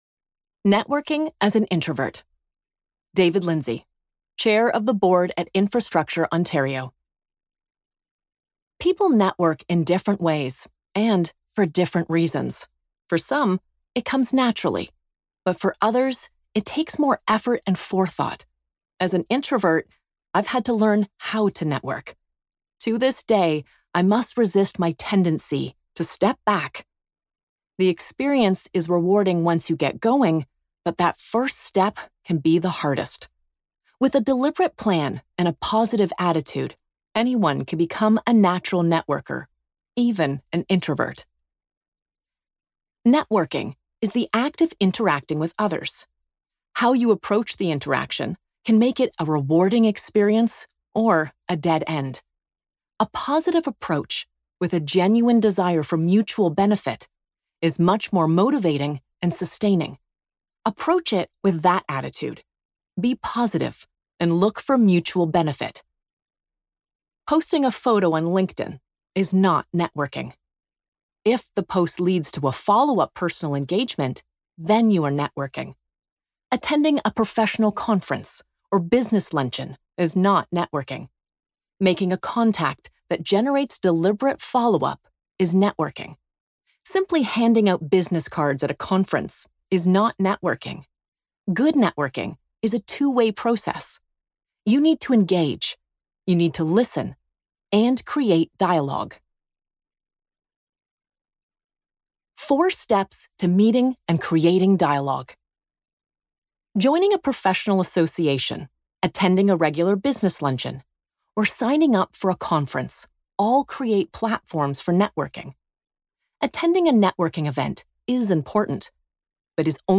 Authentic, warm and relatable.
Audiobook Sample - The Ripple Effect: Networking for Success